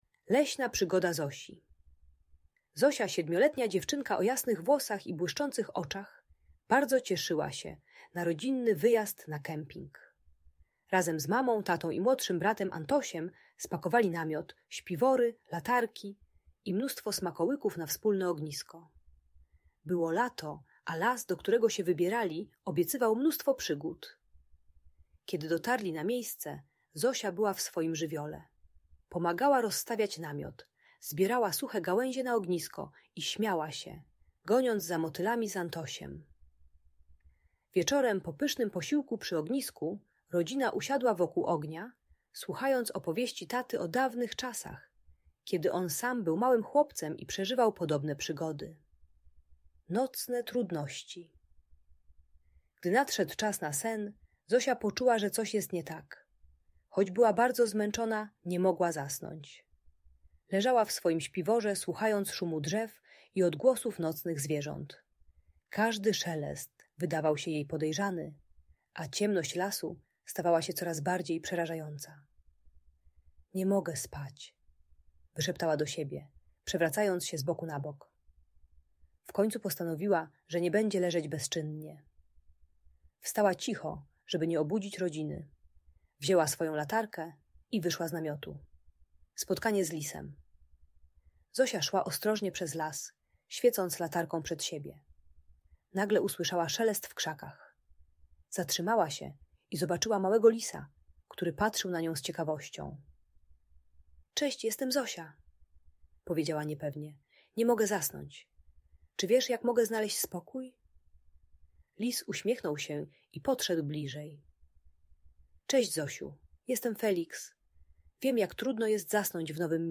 Leśna przygoda Zosi - Urocza historia dla dzieci - Audiobajka